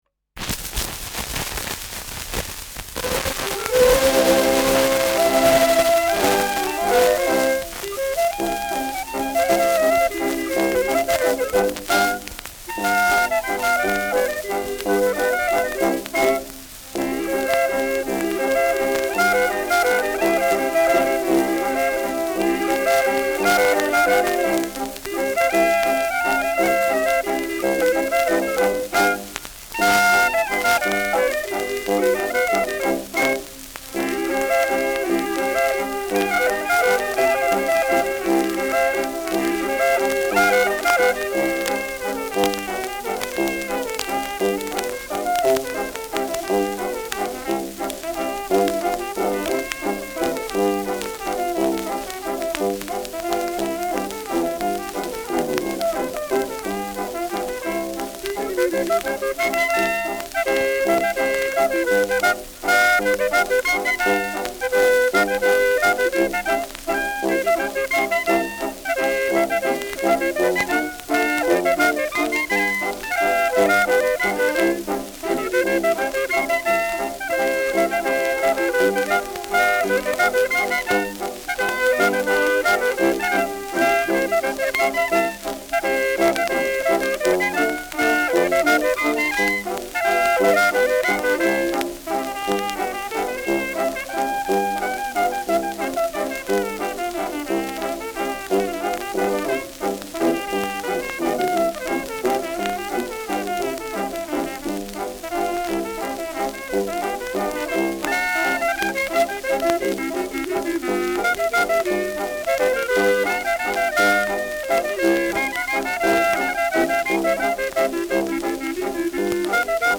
Schellackplatte
präsentes Rauschen : präsentes Knistern : abgespielt : gelegentliches Knacken : leiert : „Schnarren“ : Tonnadel „rutscht“ über einige Rillen bei 2’00’’
Dachauer Bauernkapelle (Interpretation)
Gaisberg, Frederick William (Ton)
[München] (Aufnahmeort)